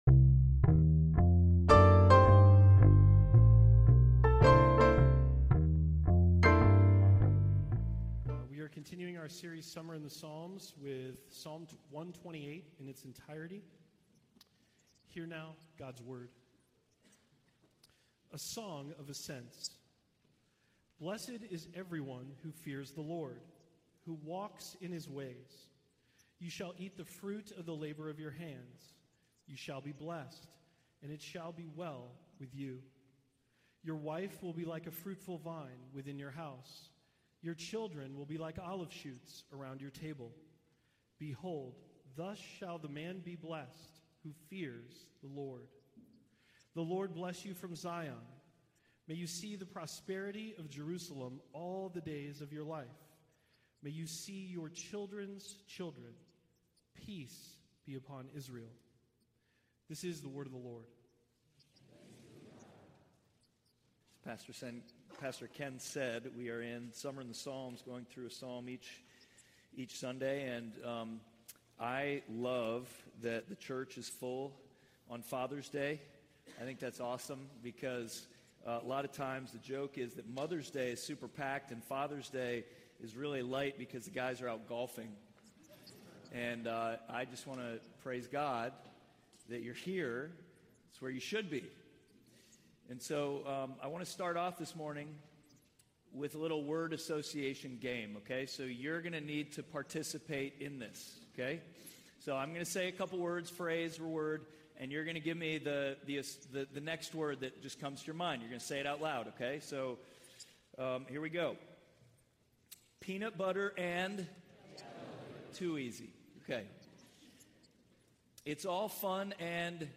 Service Type: Sunday Worship
NAPC_Sermon_6.16.24.mp3